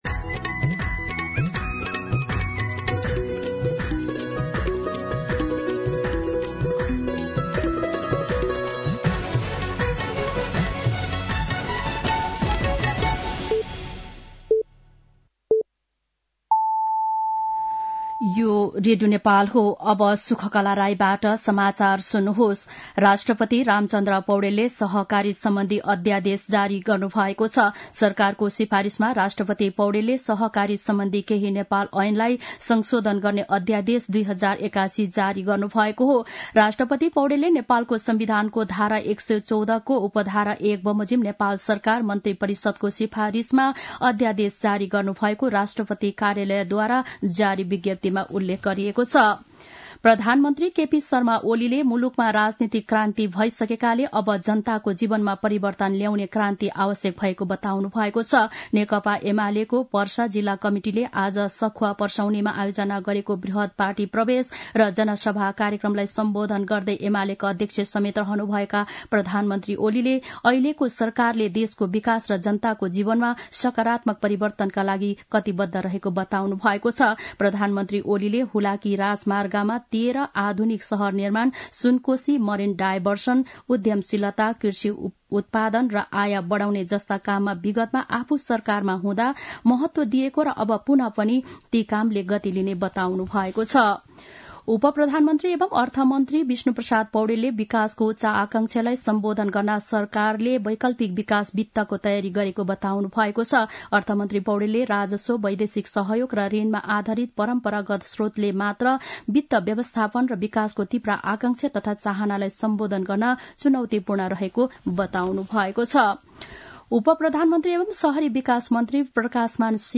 साँझ ५ बजेको नेपाली समाचार : १५ पुष , २०८१
5-PM-Nepali-News-9-14.mp3